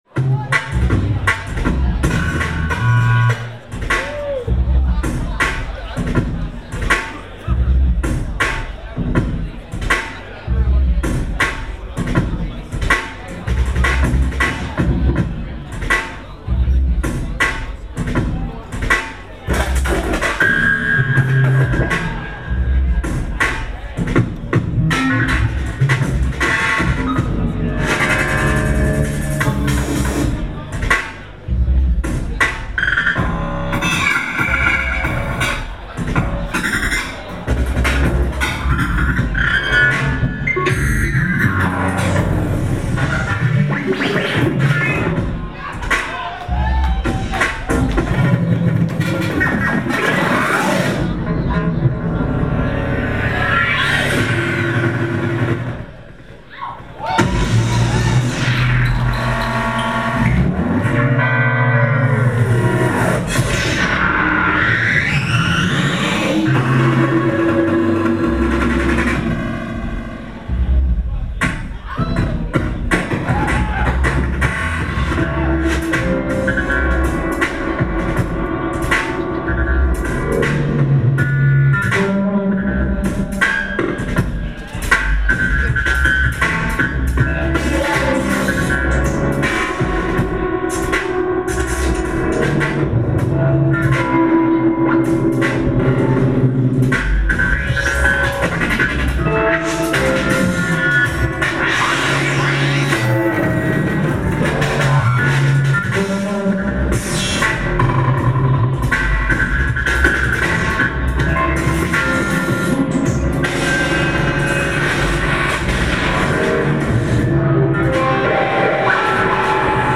location San Francisco, USA